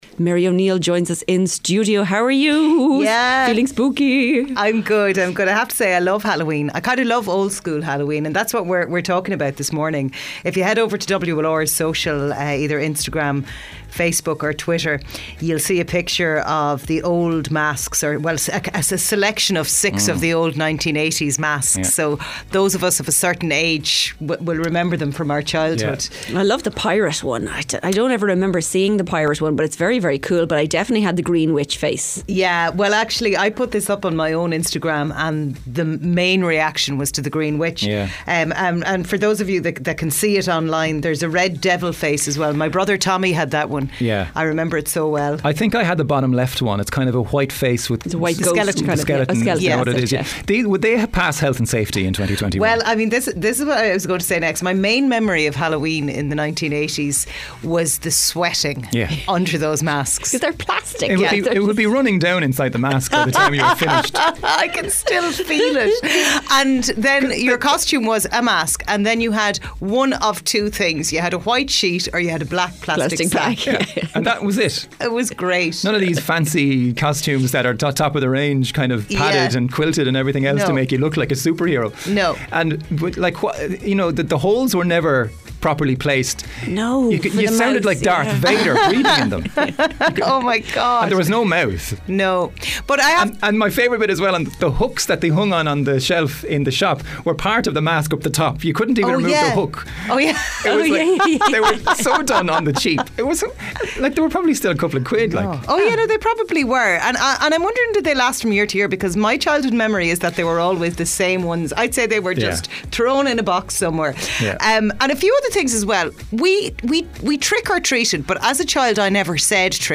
They were joined in studio